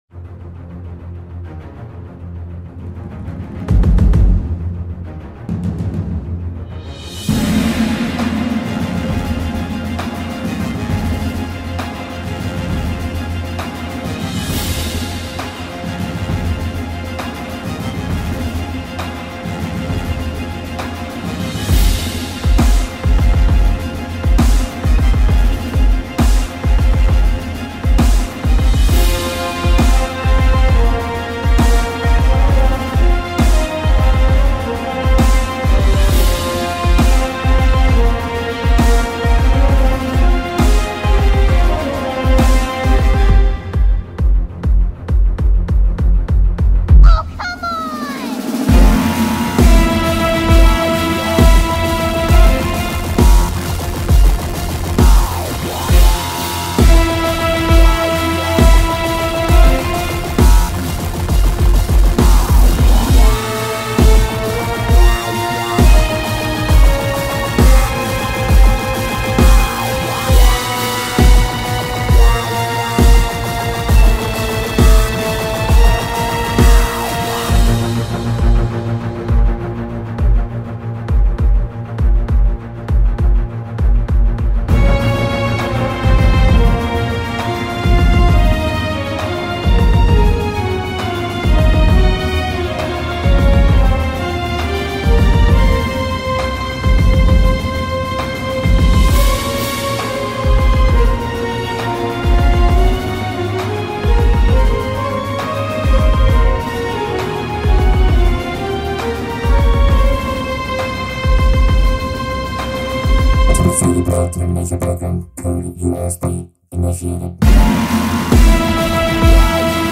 Oh man, orchestral dubstep?